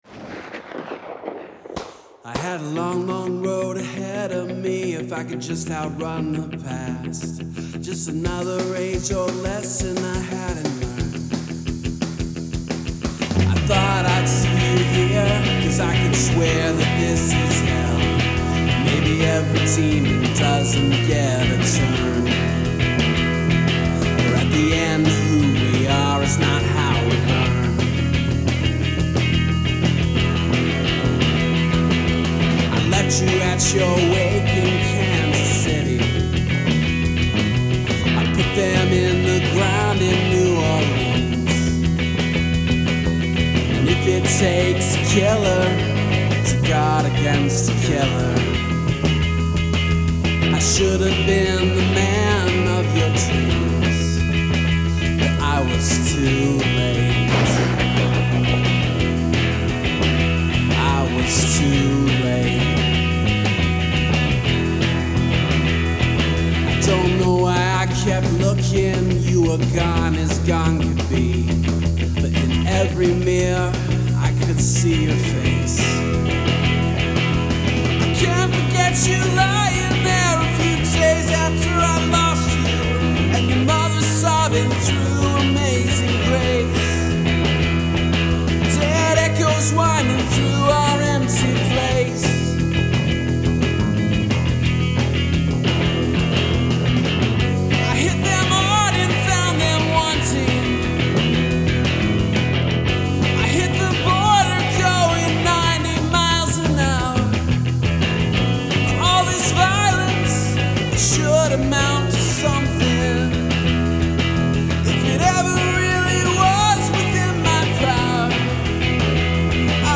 Use of the sound of gunfire
I’d like to hear more variance in the drums.
Great vocal performance!
Very 80s, especially the lead tone.
I like the beltng vocals.